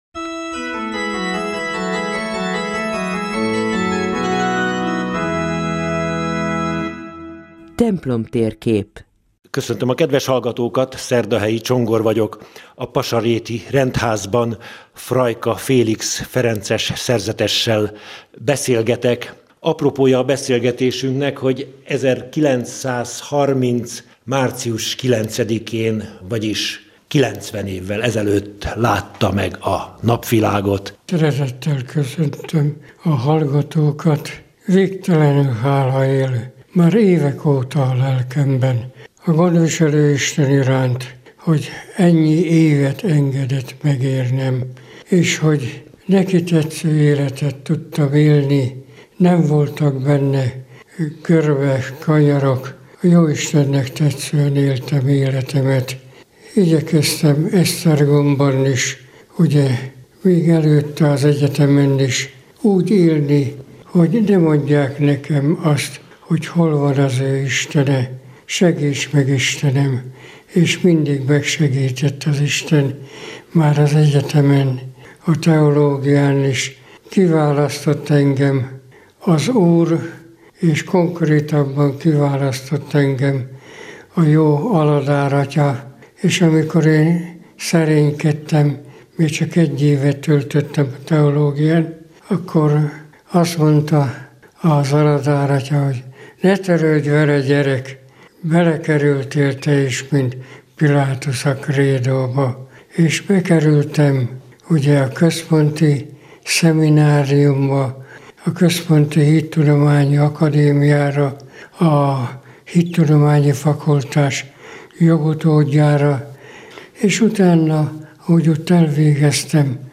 Hivatásáról, élete meghatározó eseményeiről, találkozásairól március 8-án beszélt a Katolikus Rádióban.